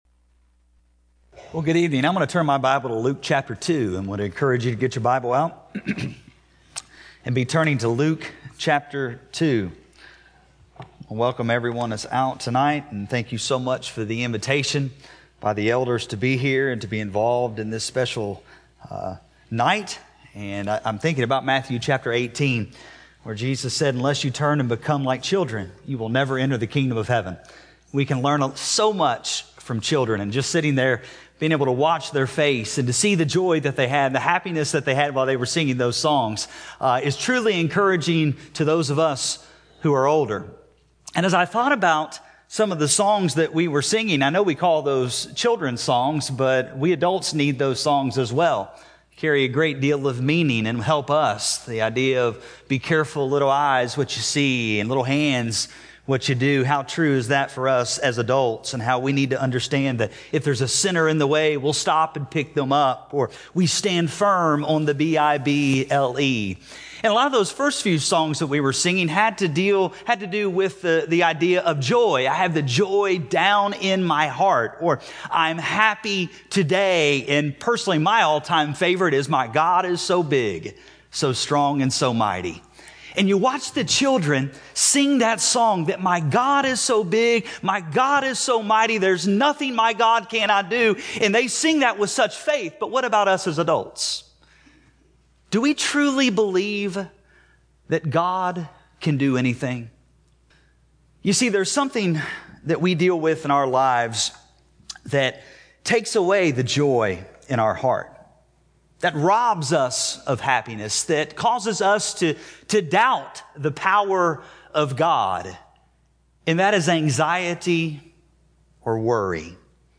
Service: Community Bible Study Type: Sermon